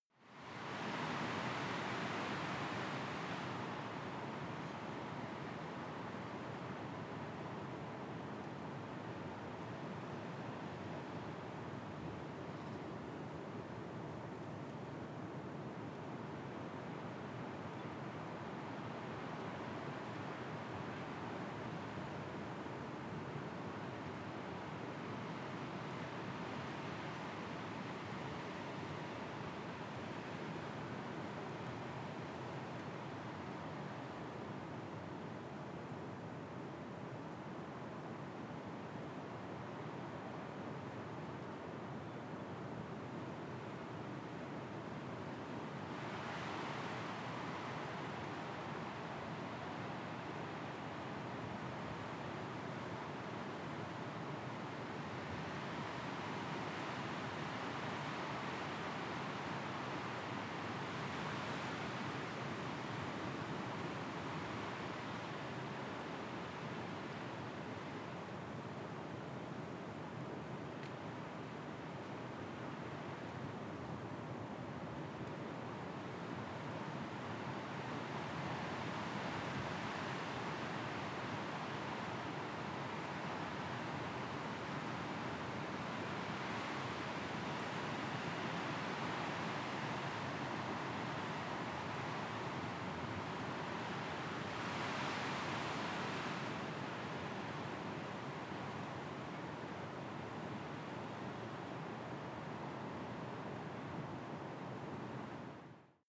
Gemafreie Sounds: Wind und Sturm